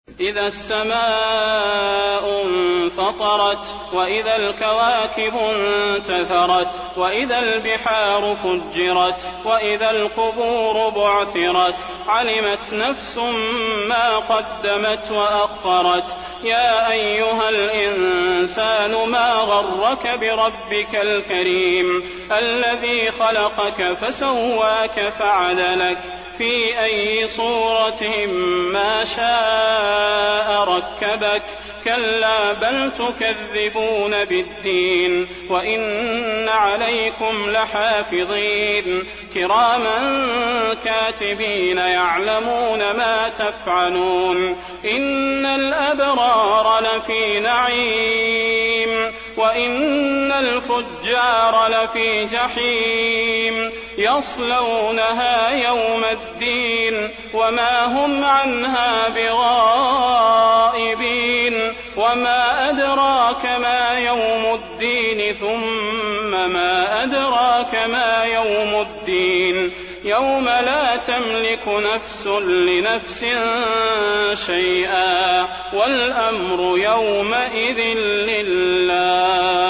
المكان: المسجد النبوي الانفطار